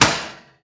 metal_impact_light_06.wav